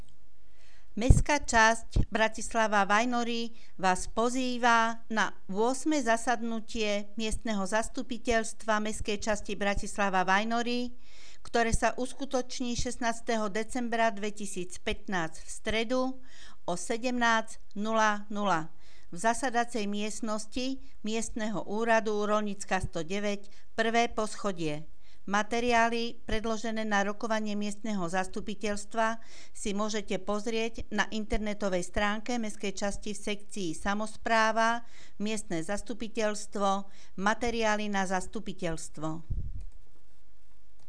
Hlásenie miestneho rozhlasu 15.,16.12.2015 (miestne zastupiteľstvo)